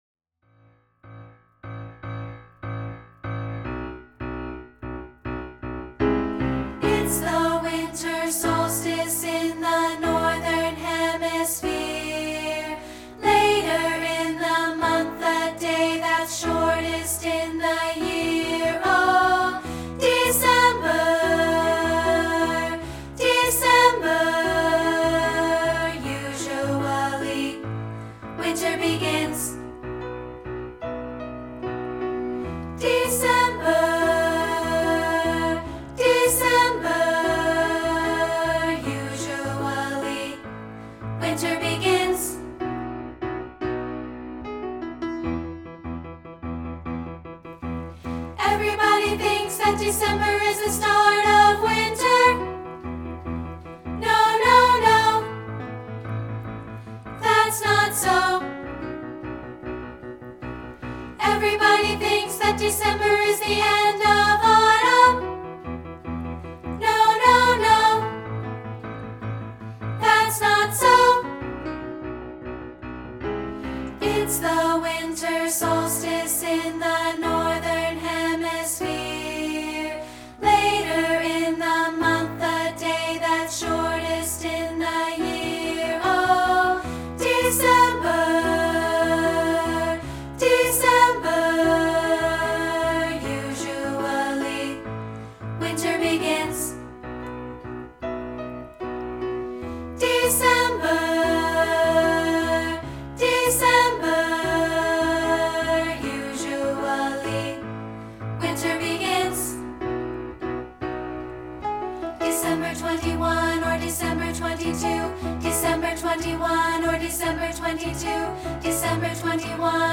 We've created a free rehearsal track of part 2, isolated